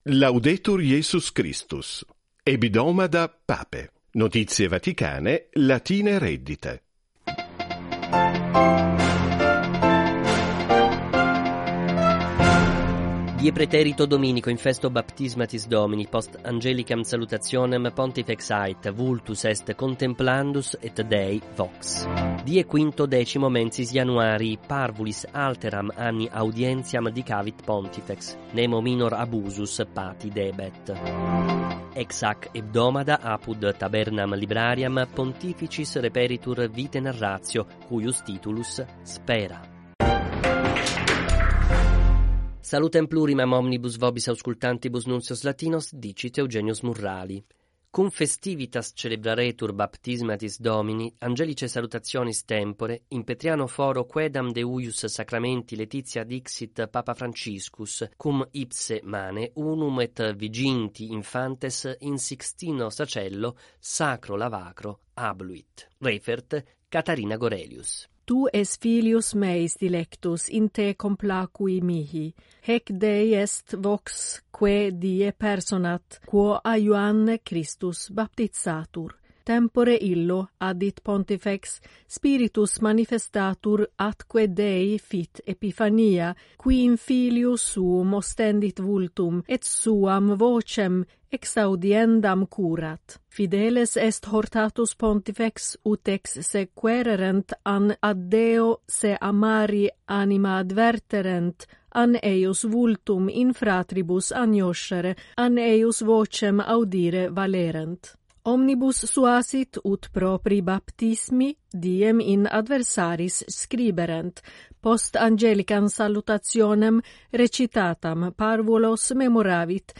Vatican Radio News in Latin 18.01.2025 4:58